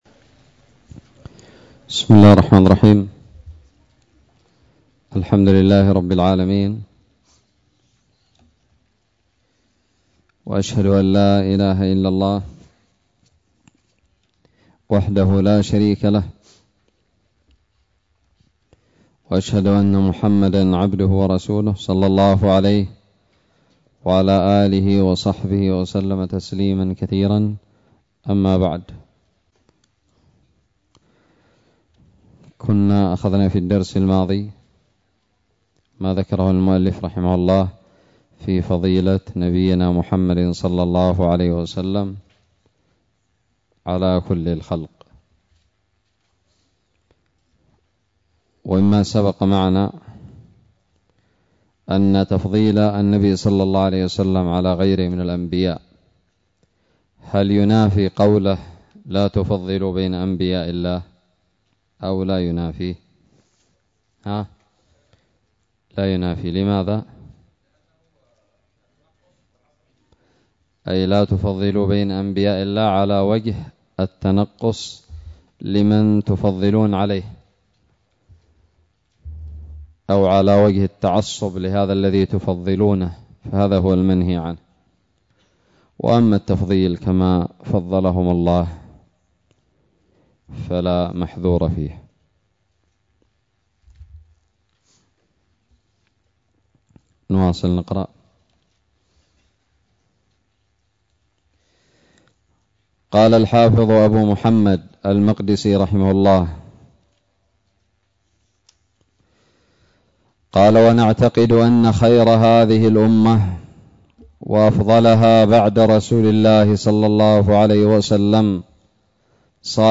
الدرس السادس والثلاثون من شرح كتاب الاقتصاد في الاعتقاد للمقدسي
ألقيت بدار الحديث السلفية للعلوم الشرعية بالضالع